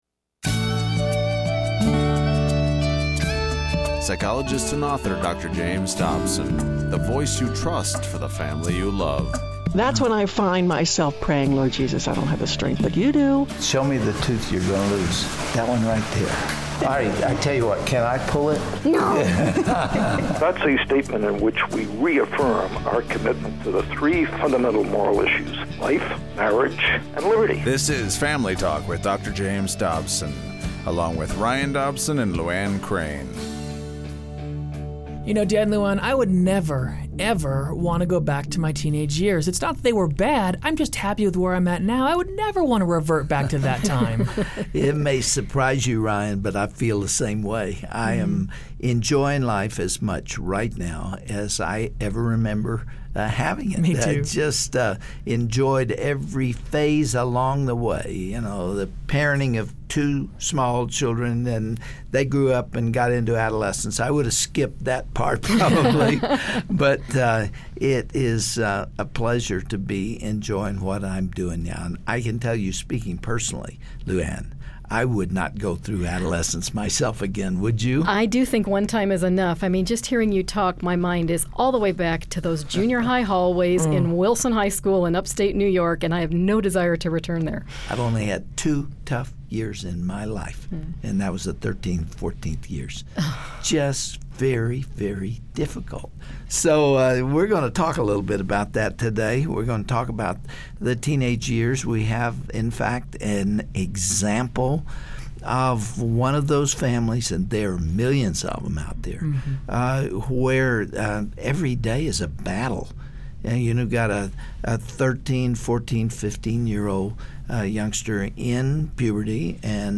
So what went wrong? On today's program we'll address those turbulent teen years by hearing from a father and daughter whose relationship had reached a critical breaking point ‚Ä¶ but are now thriving